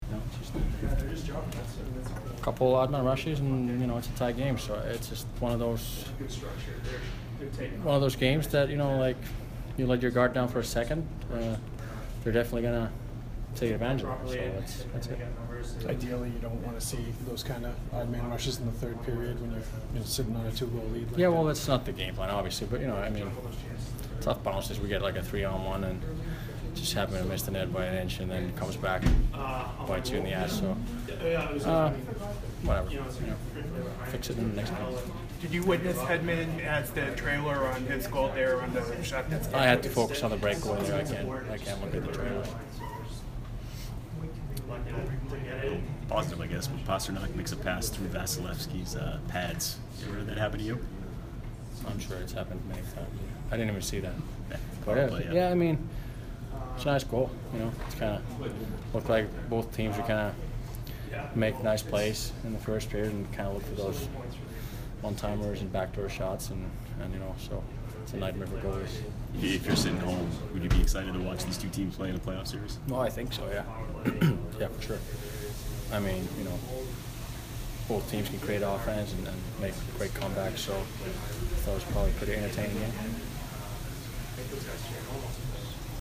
Tuukka Rask post-game 3/25